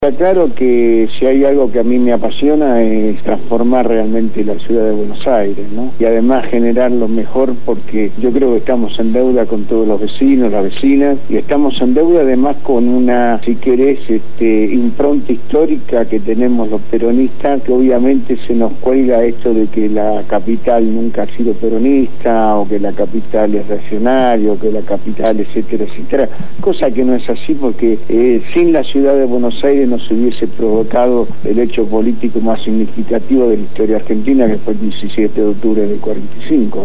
Lo afirmó Juan Carlos Dante Gullo, diputado nacional del Frente Para la Victoria y candidato a Legislador de la Ciudad de Buenos Aires por esa fuerza política en las próximas elecciones del 10 de julio de 2011, donde también se elige Jefe y Vicejefe de Gobierno y a los integrantes de las Juntas Comunales en las 15 Comunas porteñas, quien fue entrevistado en el programa «Punto de Partida» de Radio Gráfica FM 89.3